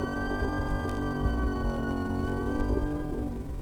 Vinyl_Tone_Layer_02.wav